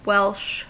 Ääntäminen
Vaihtoehtoiset kirjoitusmuodot Welsh welch Ääntäminen UK : IPA : /wɛlʃ/ US : IPA : [wɛlʃ] Haettu sana löytyi näillä lähdekielillä: englanti Käännöksiä ei löytynyt valitulle kohdekielelle.